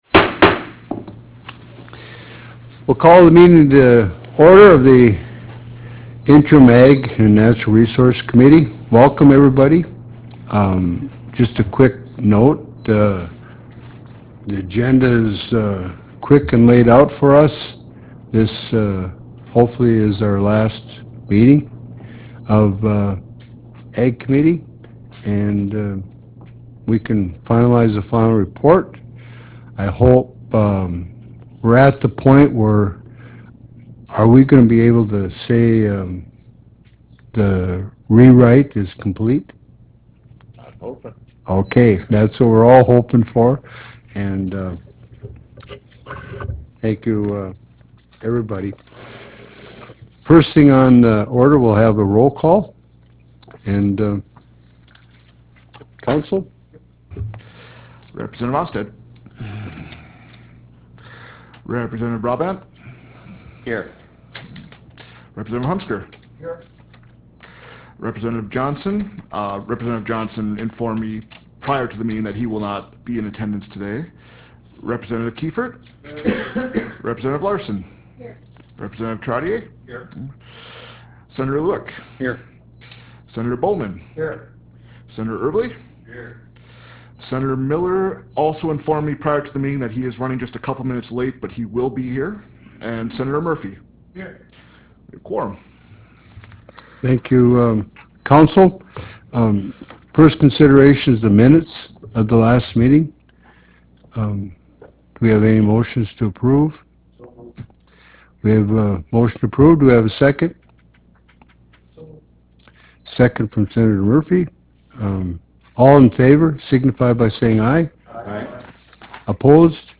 Roughrider Room State Capitol Bismarck, ND United States
Meeting Audio